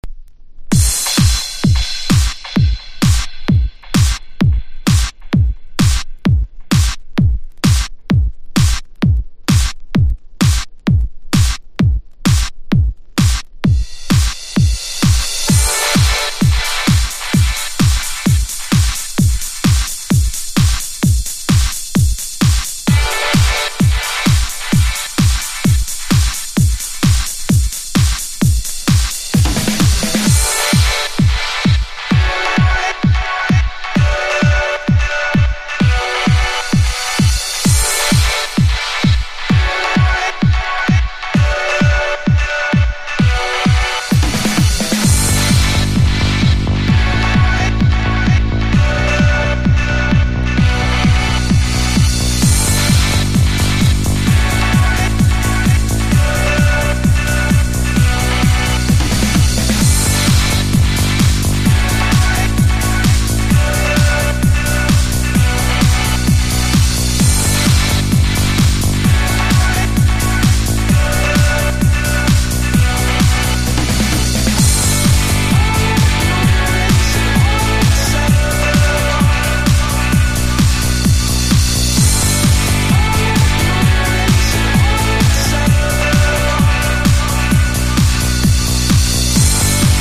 • 盤面 : EX+ (美品) キズやダメージが無く音質も良好